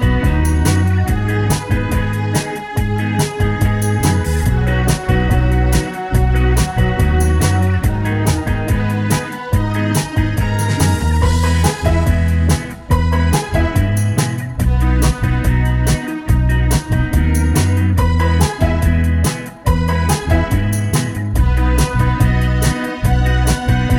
Two Semitones Down Pop (2000s) 3:12 Buy £1.50